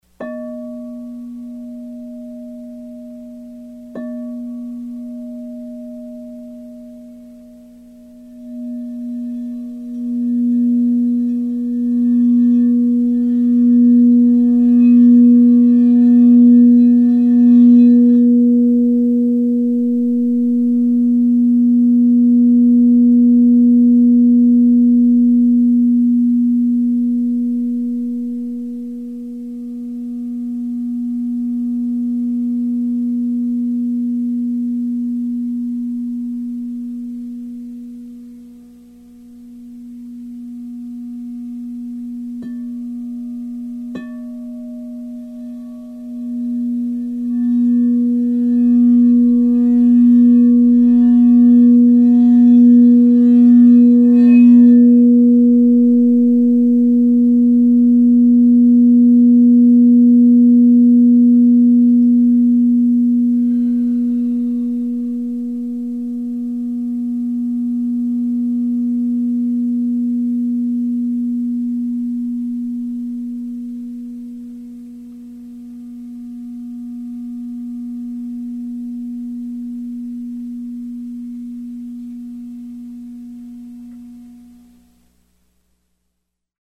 Singing Bowls
The internal spiral structure and the external rounded form of Crystal Singing Bowls produce a non linear, multidirectional Sound.
This process makes the bowls strong and incredibly pure in tone.